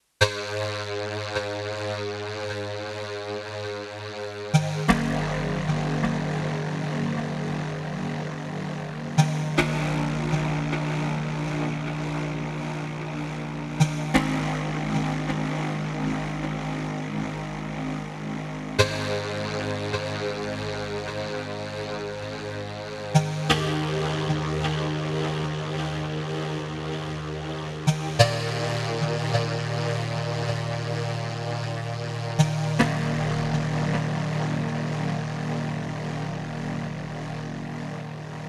I am loving the sound of this synth, it sounds soo full whatever type of sound you conjure up. No effects mind you except some delay :wink: